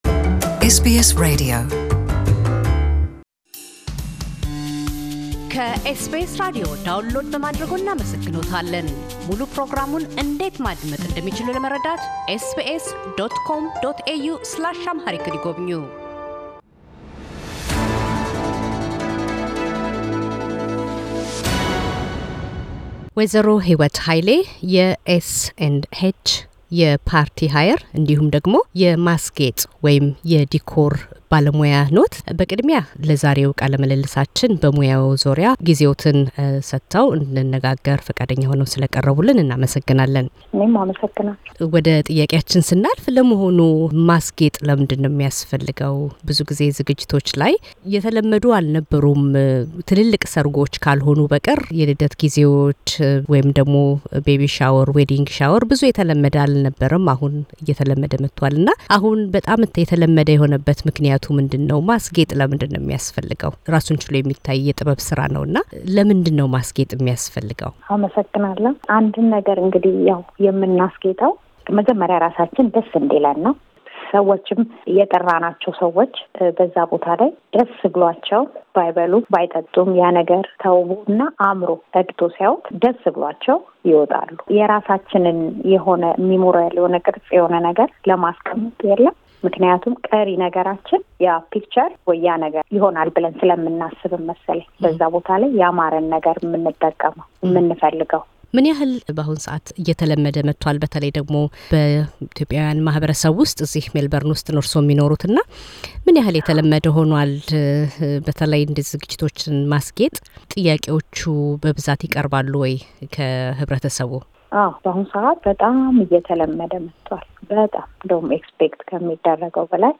ቃለ ምልልስ